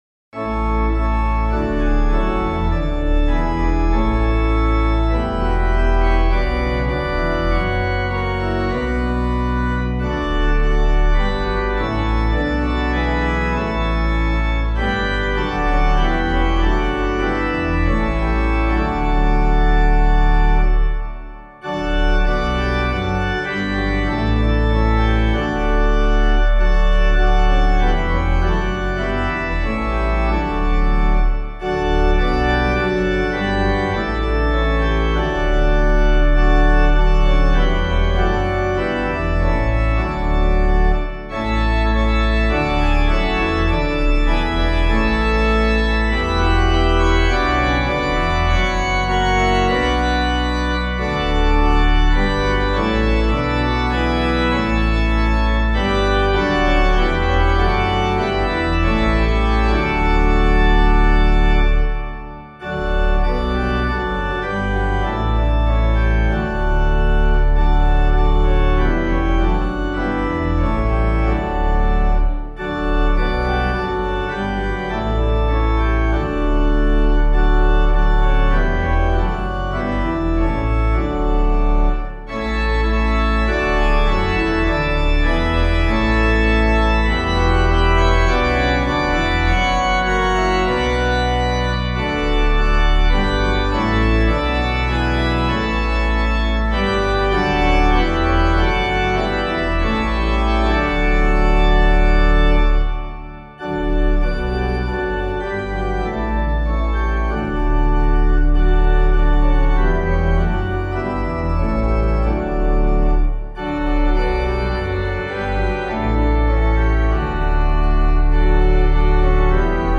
organpiano